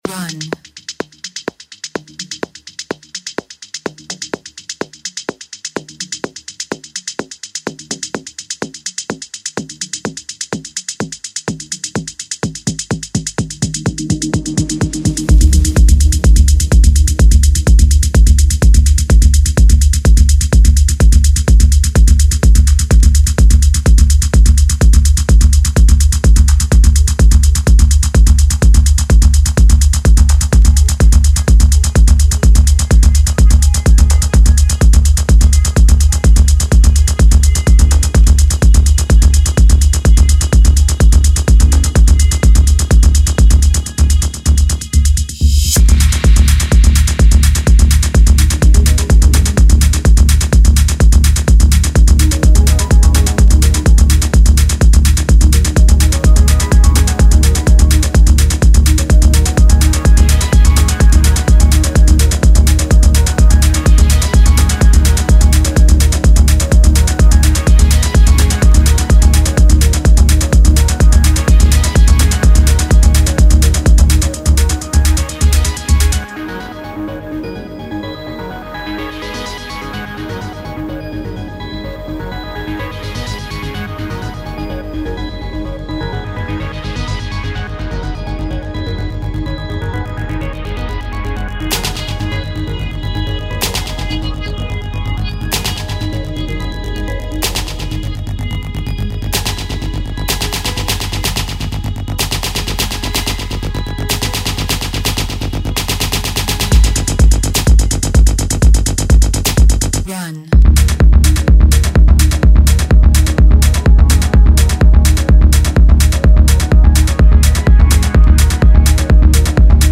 Techno Sample Pack mit Loops, One-Shots und MIDI-Dateien. Mit hochwertigen Sounds, Synthesizer-Texturen und Melodien bis hin zu pulsierenden Basslines.